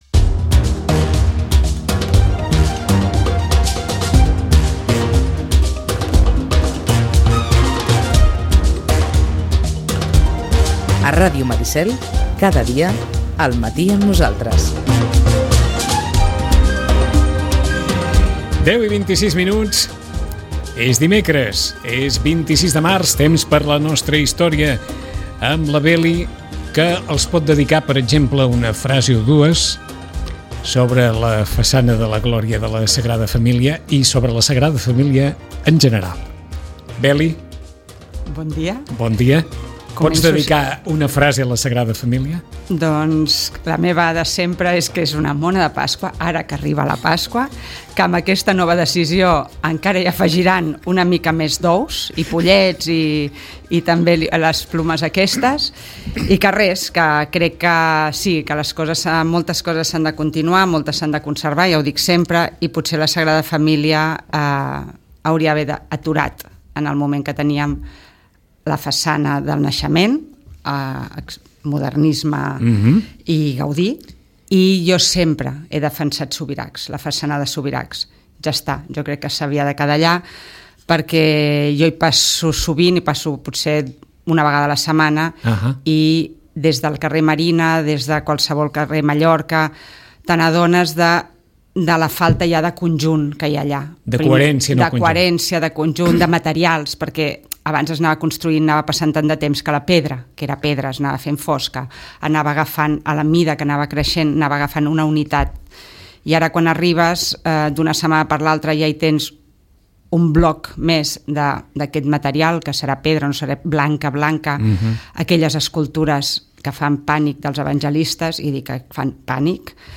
La conversa ha començat per aquí i ens ha servit per a reivindicar el paper fonamental que les postals i les cartes poden tenir a l'hora de reconstruir les històries familiars.